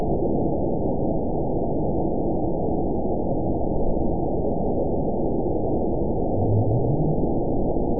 event 920638 date 04/02/24 time 02:05:01 GMT (1 year, 1 month ago) score 9.64 location TSS-AB03 detected by nrw target species NRW annotations +NRW Spectrogram: Frequency (kHz) vs. Time (s) audio not available .wav